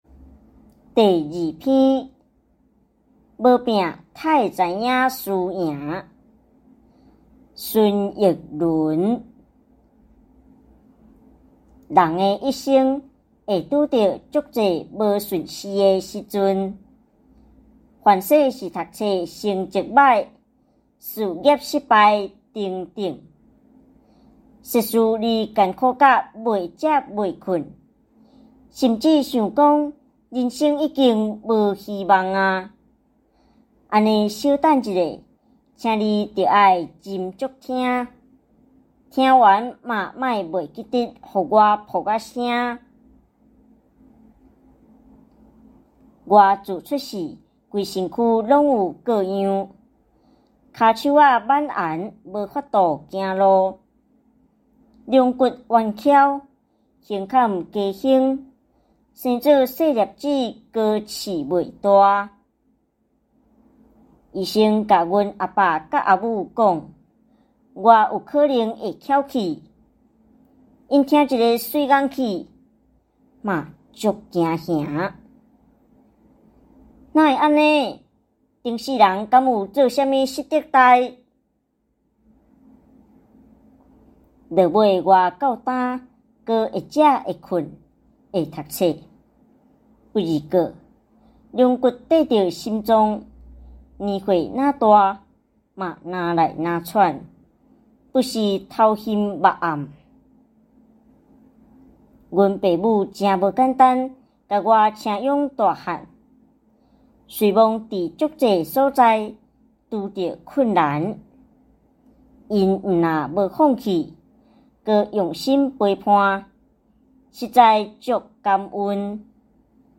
114臺灣台語朗讀音檔1-無拚，呔會知影輸贏.m4a